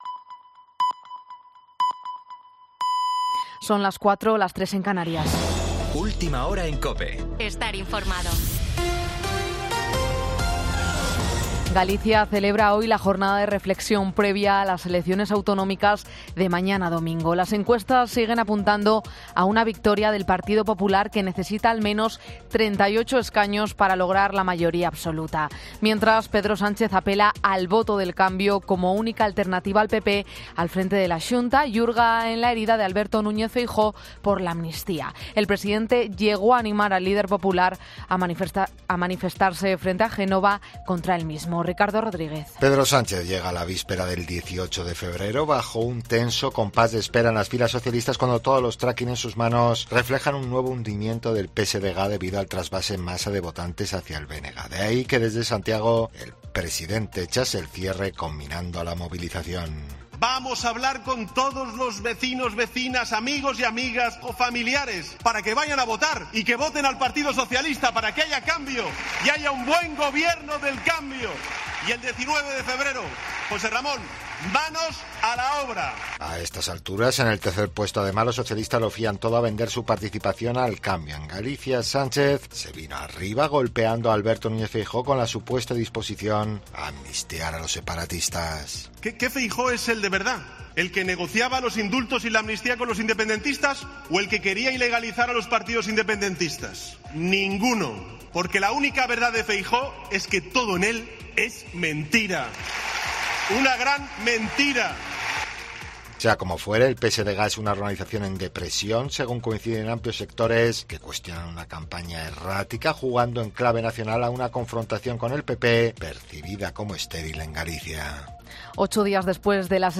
Boletín 04.00 horas del 17 de febrero de 2024